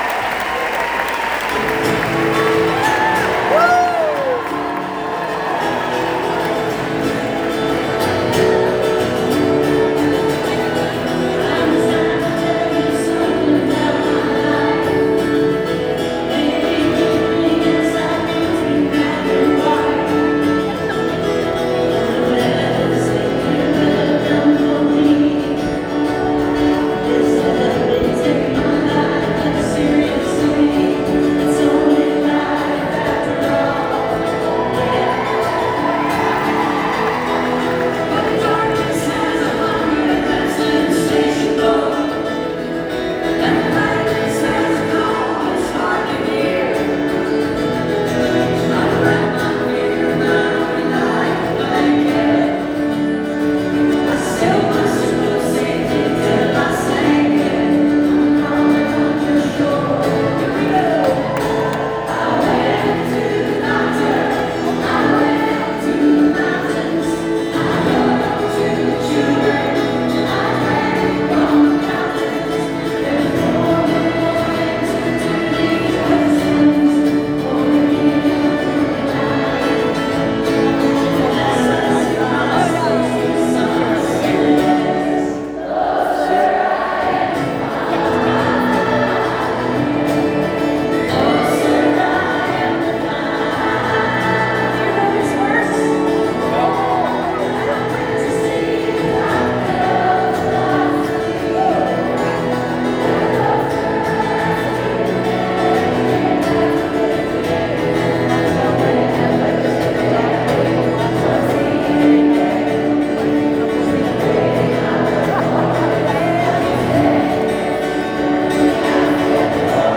(audio capture from a facebook live stream)